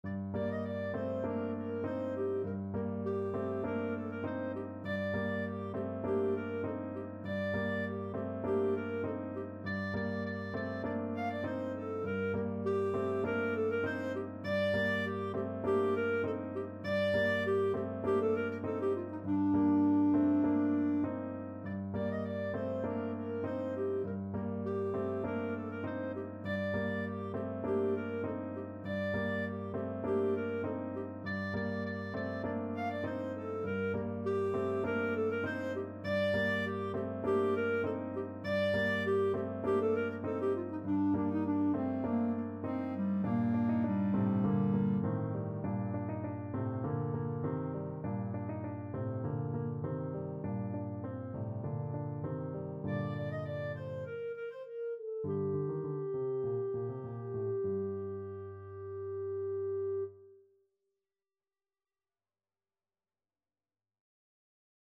Allegro (View more music marked Allegro)
4/4 (View more 4/4 Music)
Classical (View more Classical Clarinet Music)